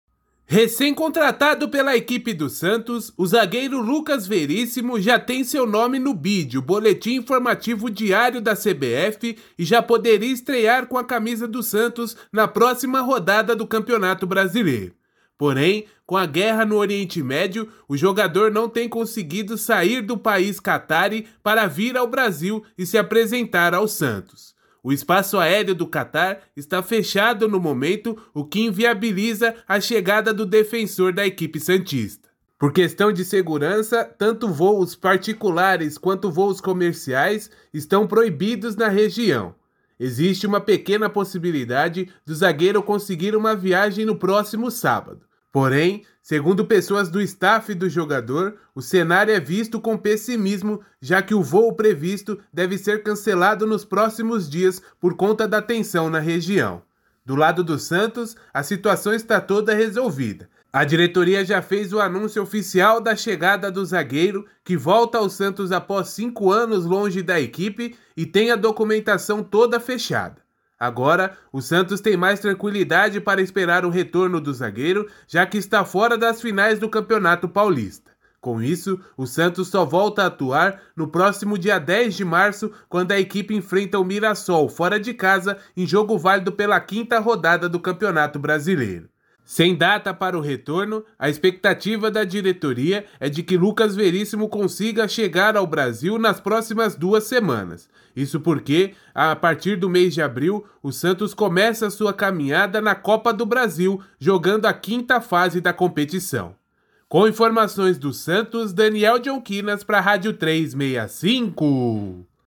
Boletin em áudio